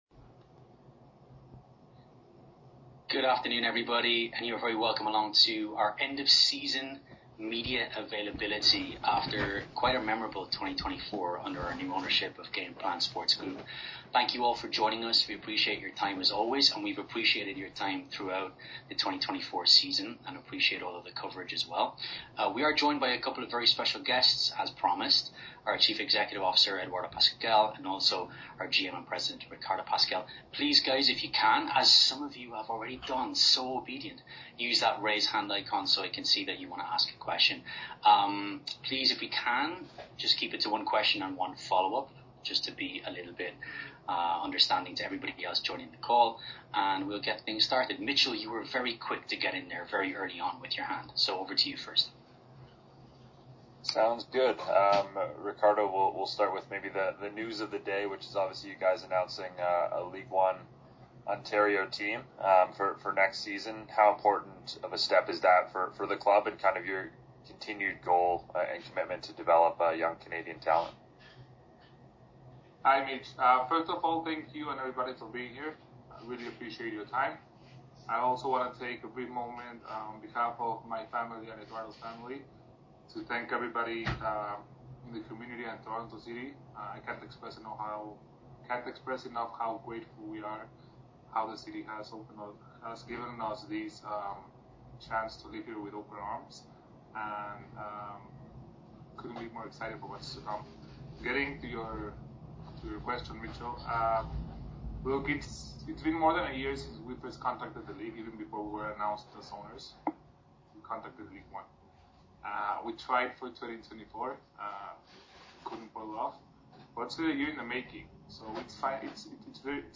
November 14, 2024...York United end of season media conference
A few questions in Spanish.